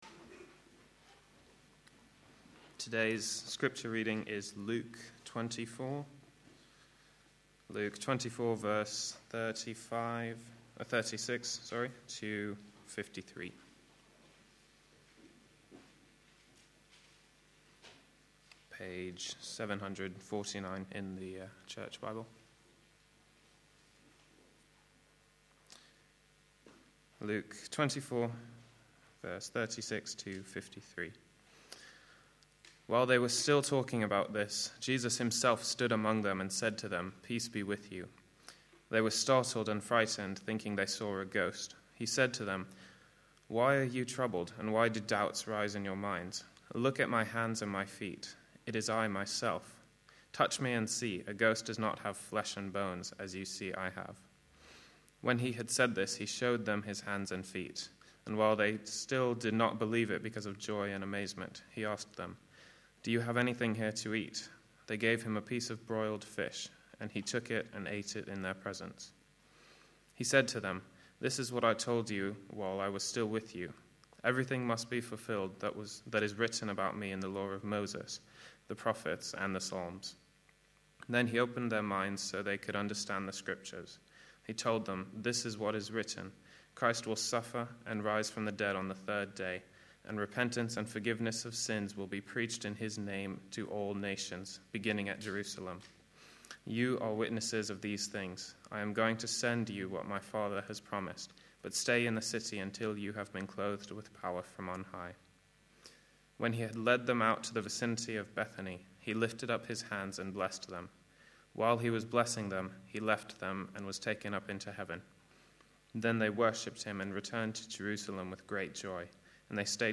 A sermon preached on 25th September, 2011, as part of our Distinctives series.